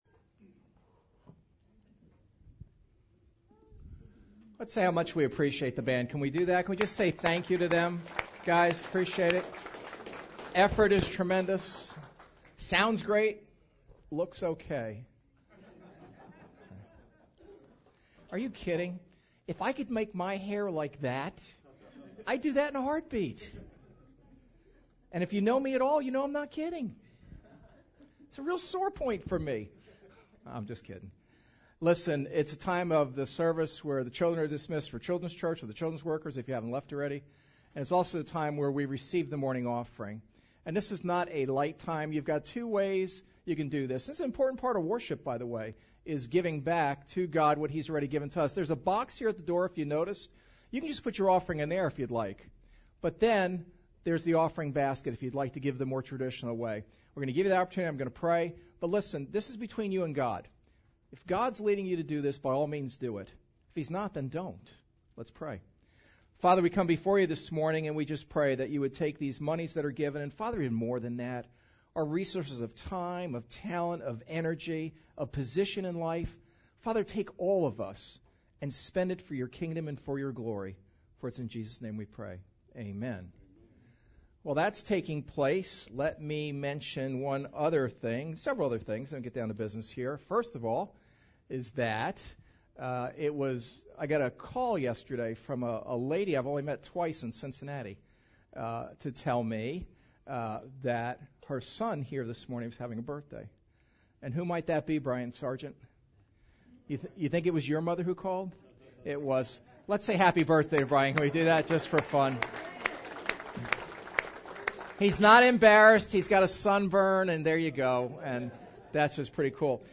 Philippians 4:10-19 Service Type: Sunday Service ARE YOU CONTENT?